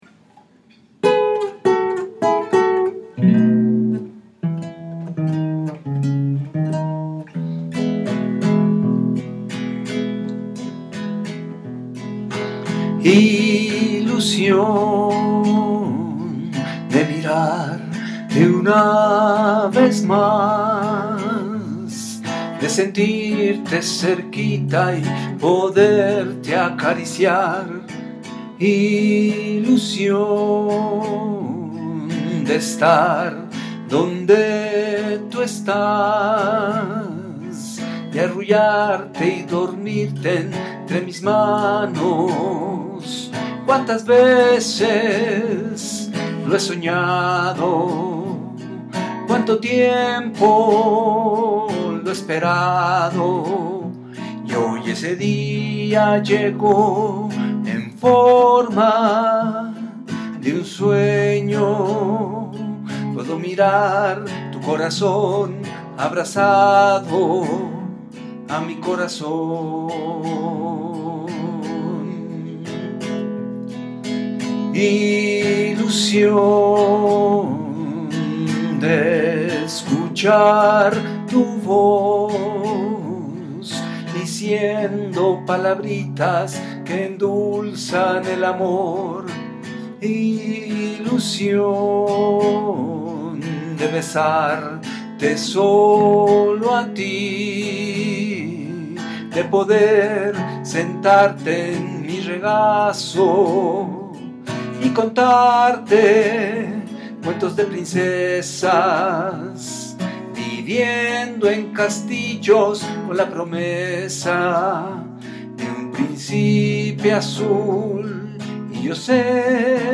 ranchera